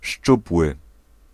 Ääntäminen
IPA: /dʏn/